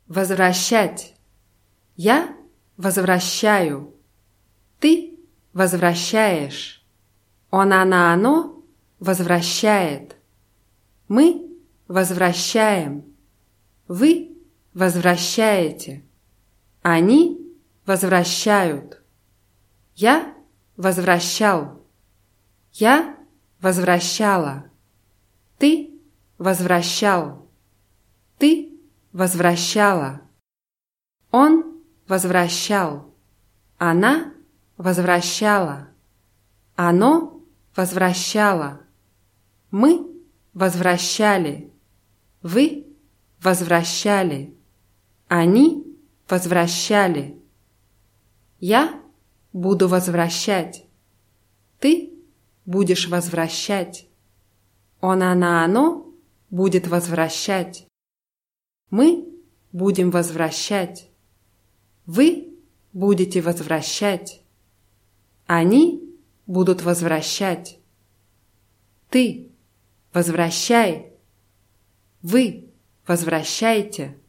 возвращать [wazwraschtschátʲ]